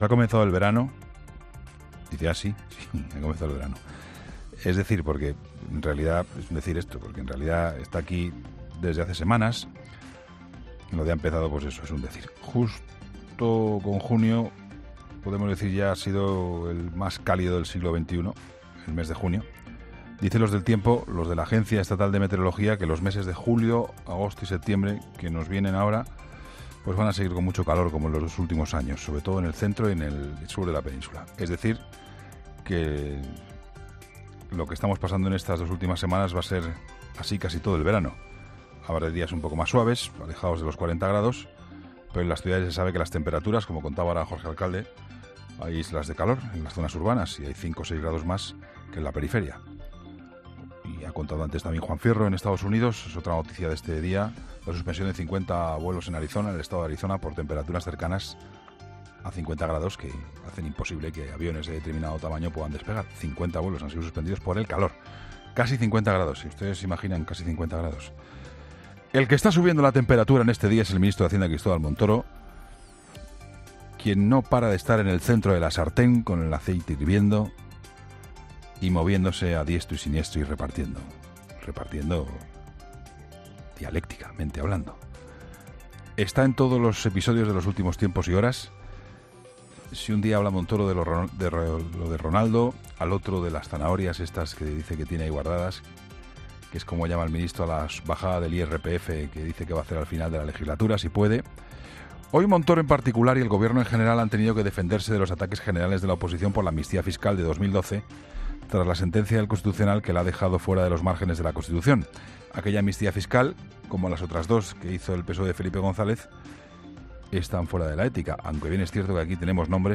AUDIO: El análisis de Juan Pablo Colmenarejo en 'La Linterna' tras proponer el ministro de Hacienda y Función Pública al Congreso prohibir las amnistías...